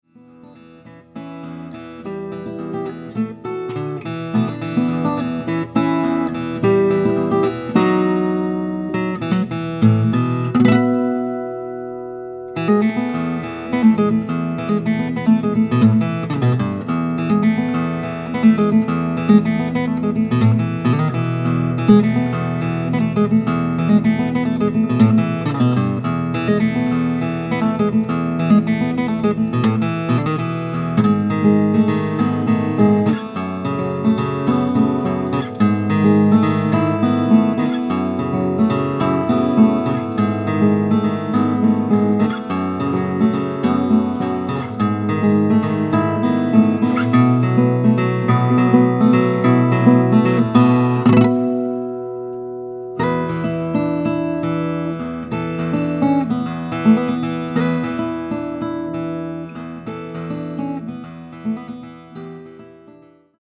●幻想的でエキセントリックなオリジナル